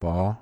중상승성조 (Mid Rising)던지다povpor
몽어 pov 발음